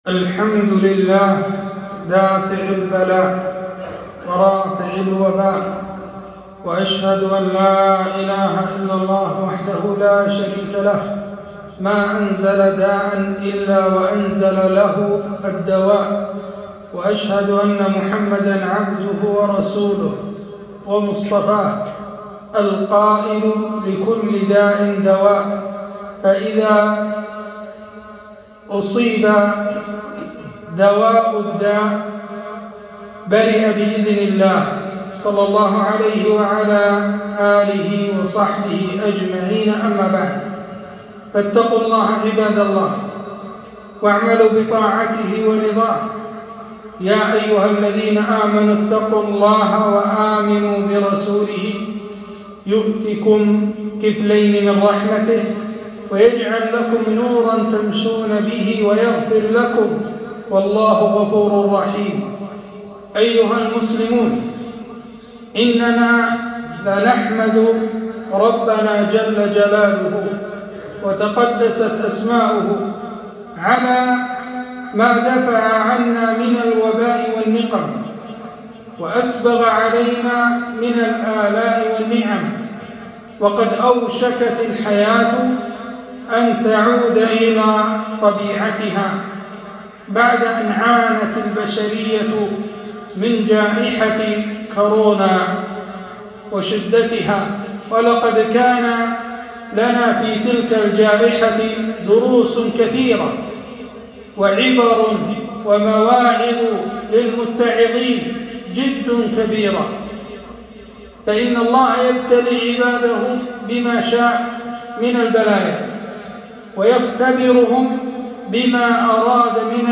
خطبة - لعلهم يرجعون